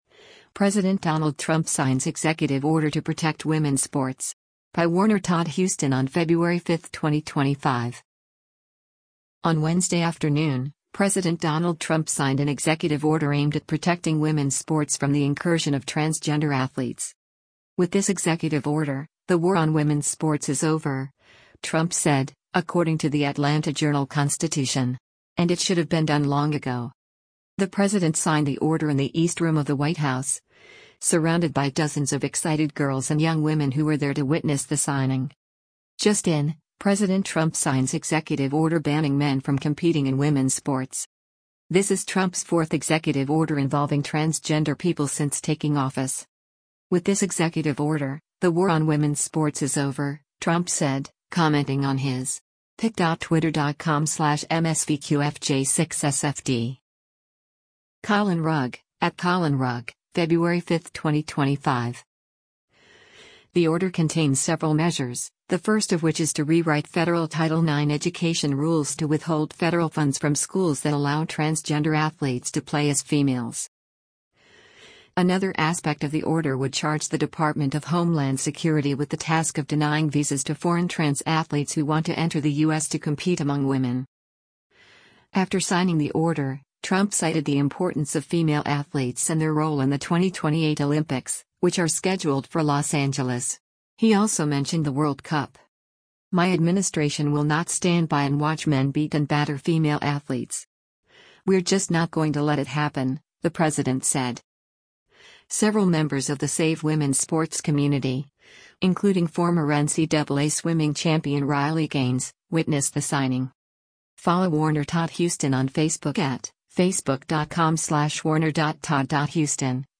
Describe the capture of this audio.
The president signed the order in the East Room of the White House, surrounded by dozens of excited girls and young women who were there to witness the signing.